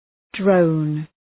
Shkrimi fonetik {drəʋn}